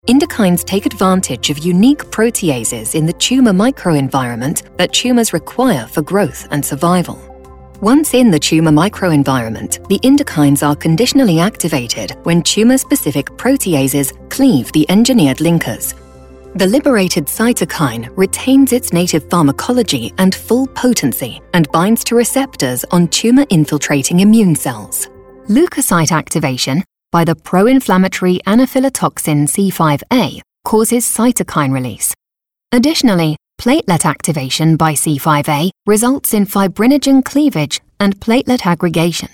Medical
accented English, authoritative, british, informative, Matter of Fact, Medical, neutral, Straight Forward, technical